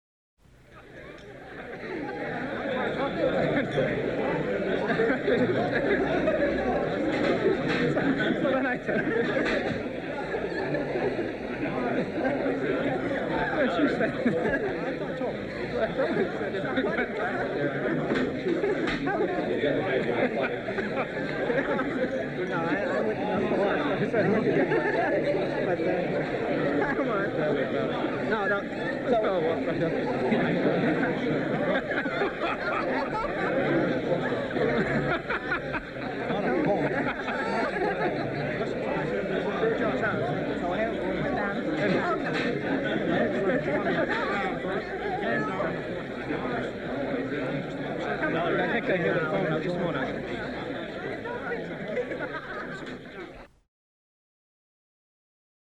Голоса гостей в разговорах на вечеринке